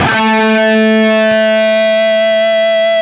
LEAD A 2 CUT.wav